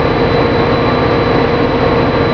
F40 out of cab sound
F40editmono1.wav